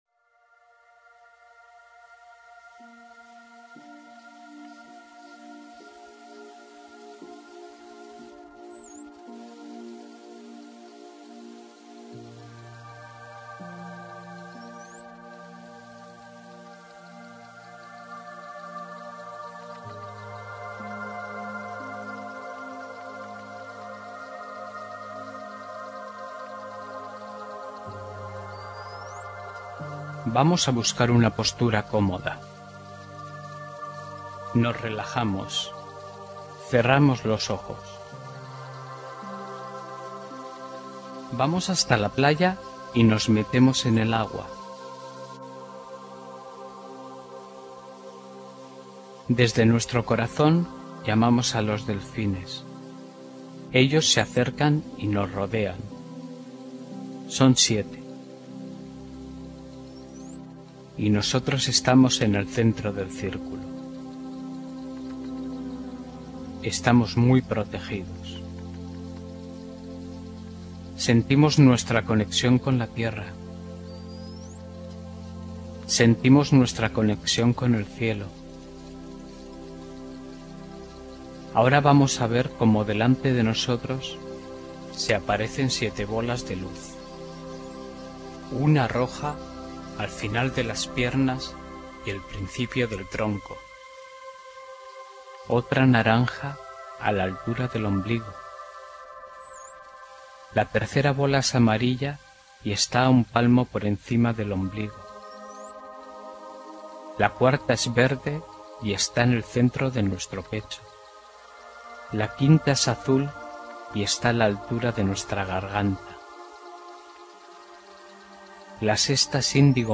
Visualizaciones para Niños y los que todavía lo somos